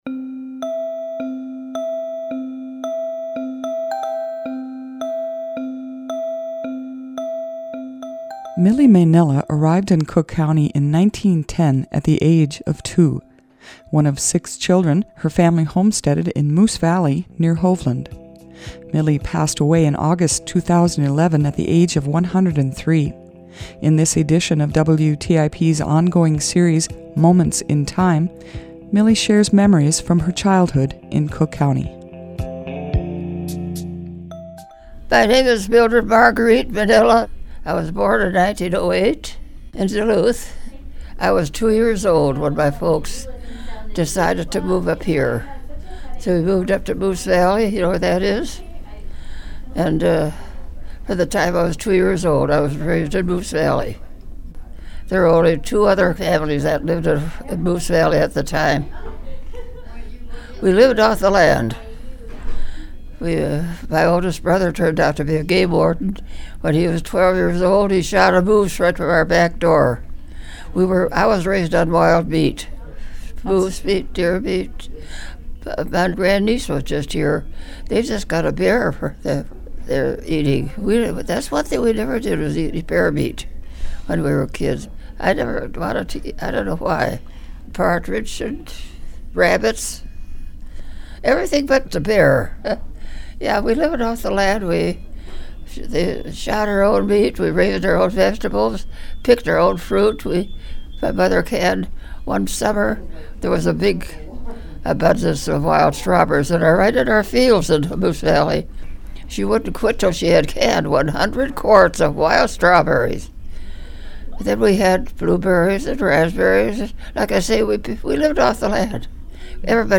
In Moments in Time, we speak with community members about their memories from different periods of our region's past to help foster an appreciation and understanding of the community in which we find ourselves today.